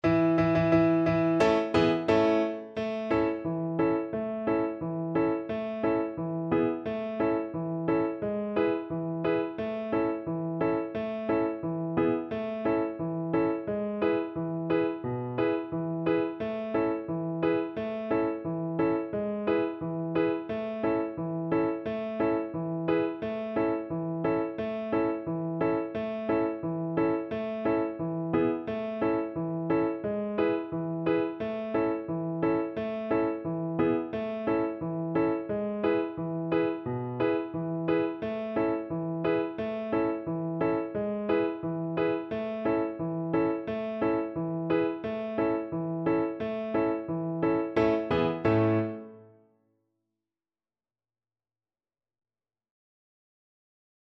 Steady march =c.88